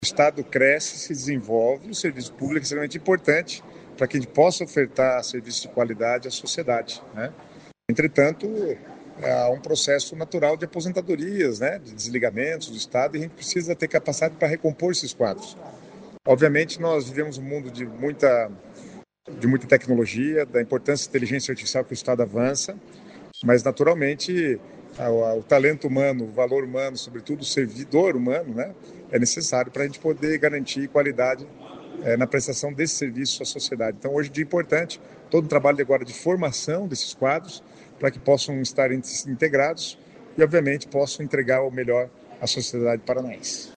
Sonora do secretário Estadual das Cidades, Guto Silva, sobre a integração de 865 novos servidores